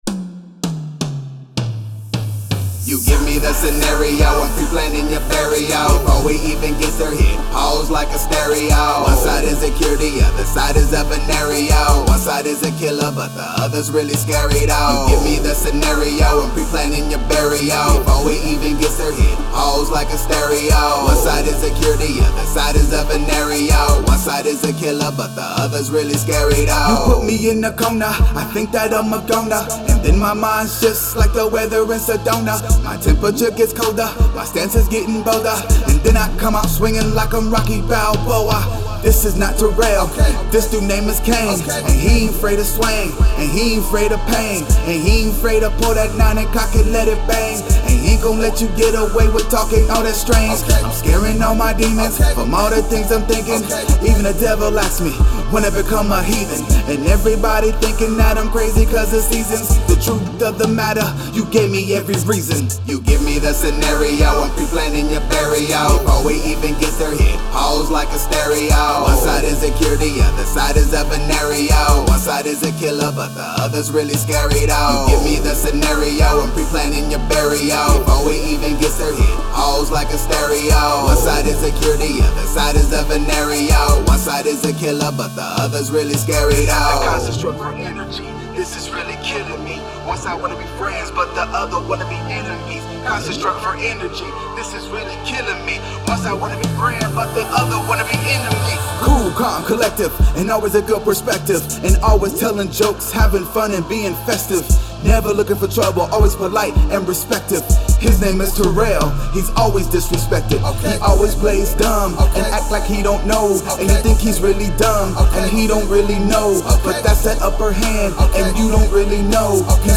Hip-hop, Pop, Rhythm and Blues and Rap/Rock